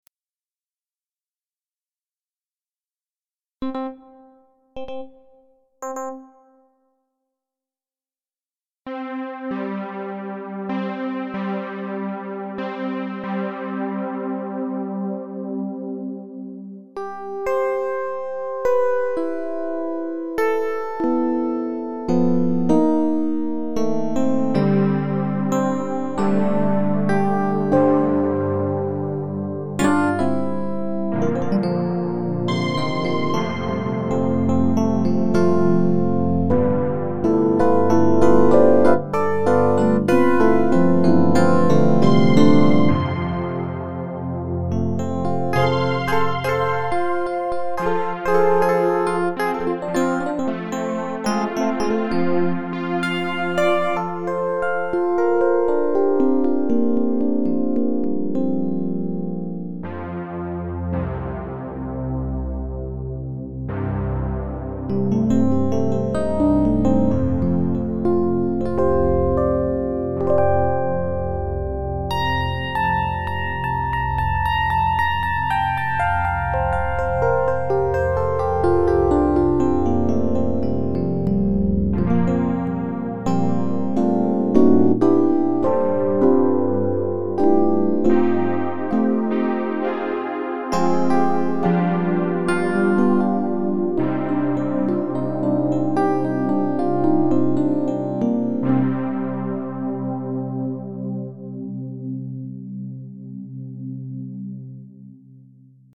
I used a seperate Qjackctl (it isn´t easy to make one working with the requires jackd protocol version) to connect midi links, a tryout of the above sound in mp3 form:
ingentry1 [.mp3] Live played electrical piano made with 3 layered (16 voice polyphonic) hexter Dx simulation sounds, combined with a strong Zynsubaddfx synth sound which has its own reverb. The DX sounds are panned in a small row in the center, and are given two types of reverb (gnuverb and plate).
Very pretty though I didn't try to use it subtle.